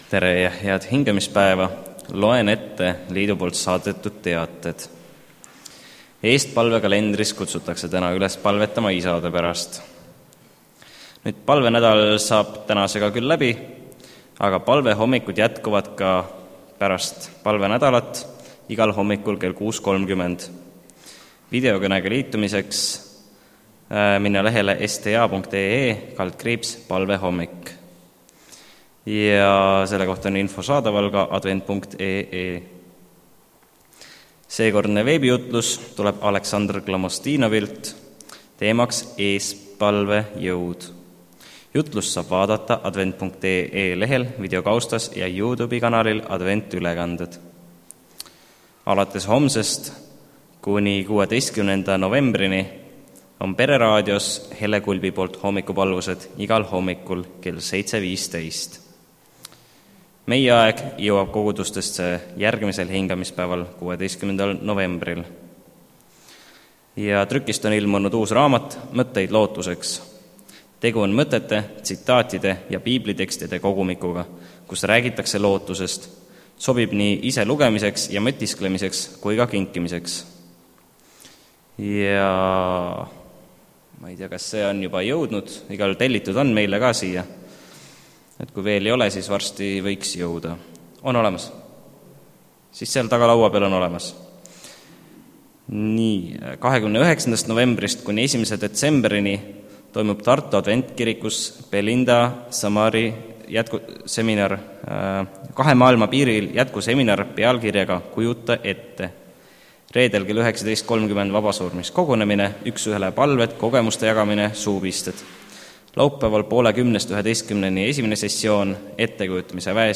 Koosolekute helisalvestused
Täna on Haapsalus külas õed-vennad Lootuse külast
Kaks laulu kooslaulmiseks.